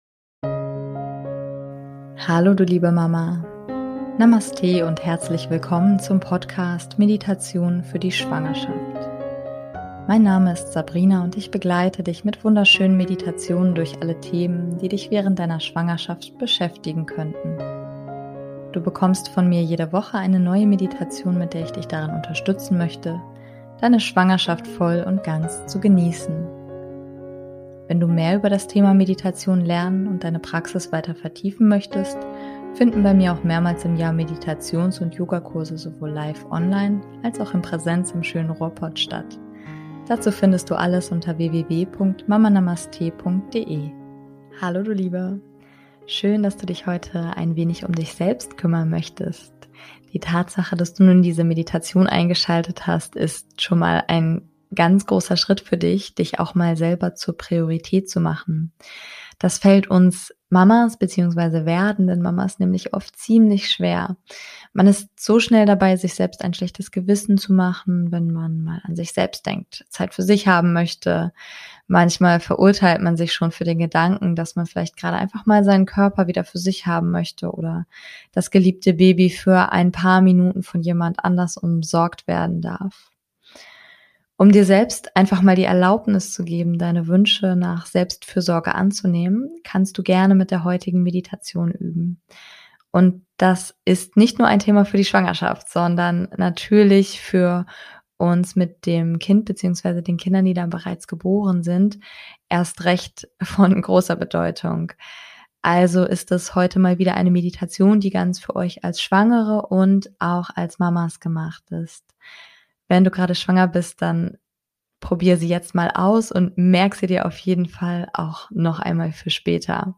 #063 - Selfcare Meditation - Deine Selbstliebe als Schwangere & Mama ~ Meditationen für die Schwangerschaft und Geburt - mama.namaste Podcast